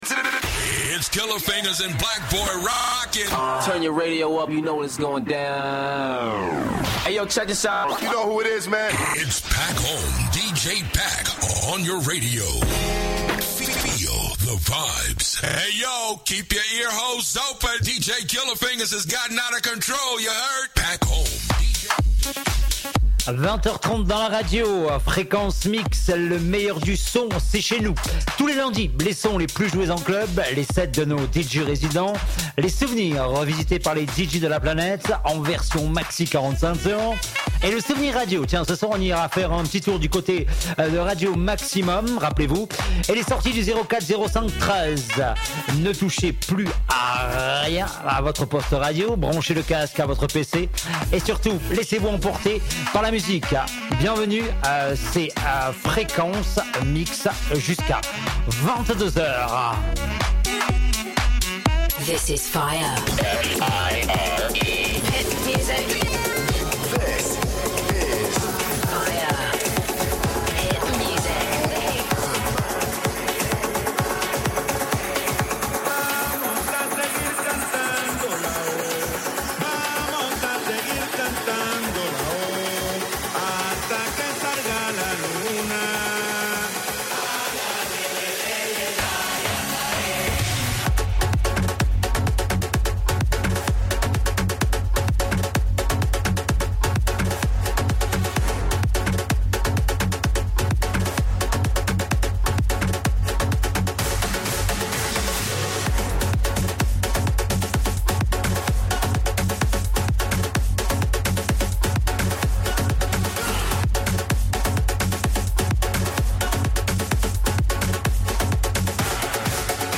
EN LIVE